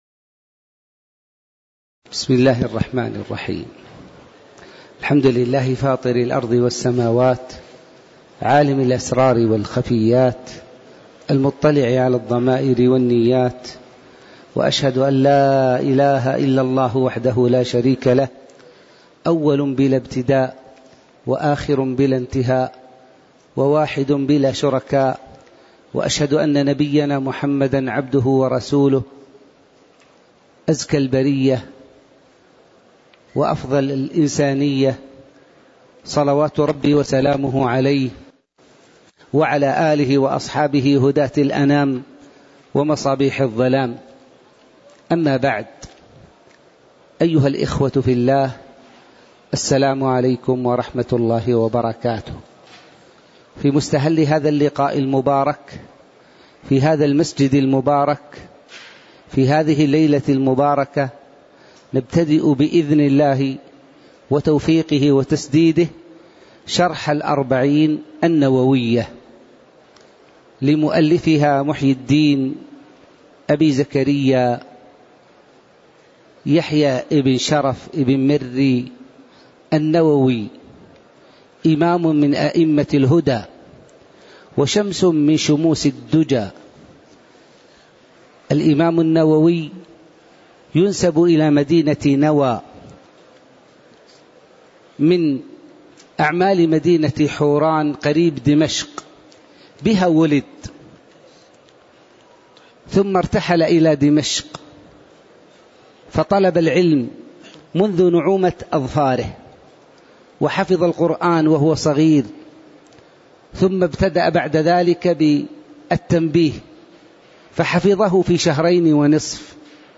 تاريخ النشر ٣ جمادى الآخرة ١٤٣٧ هـ المكان: المسجد النبوي الشيخ